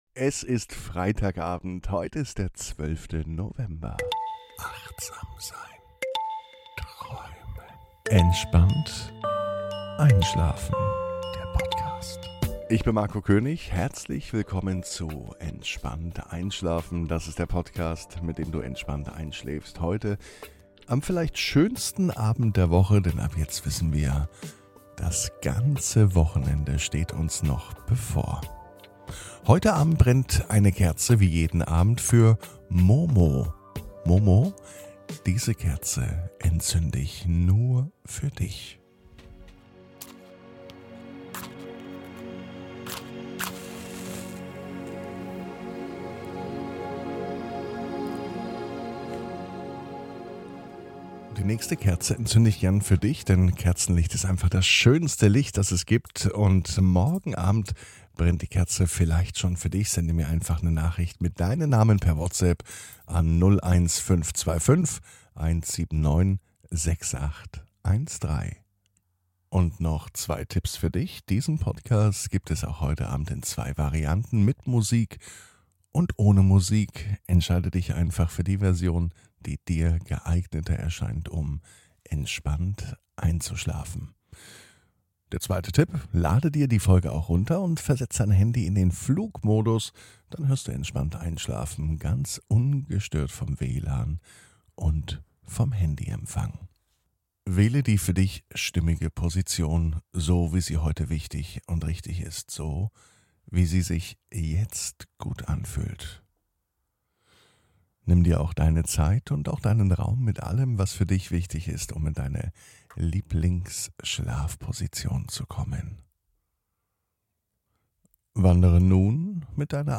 (ohne Musik) Entspannt einschlafen am Freitag, 12.11.21 ~ Entspannt einschlafen - Meditation & Achtsamkeit für die Nacht Podcast